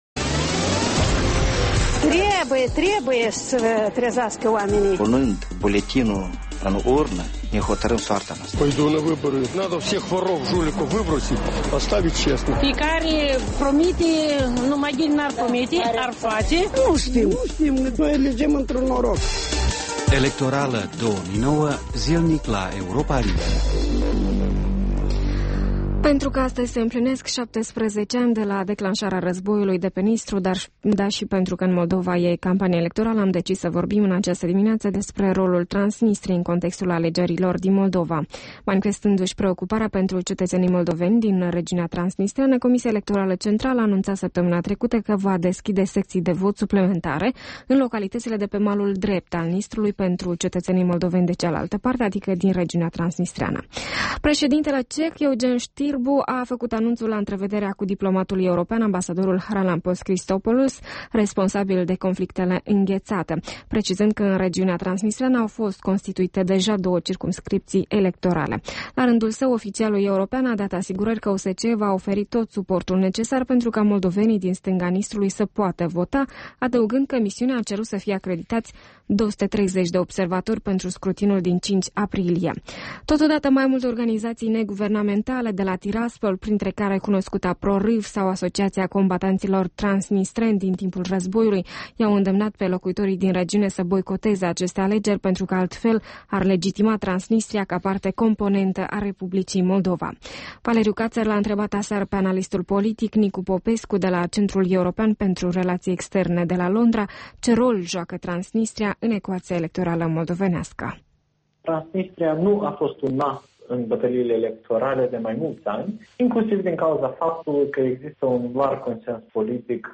Un interviu cu expertul Nicu Popescu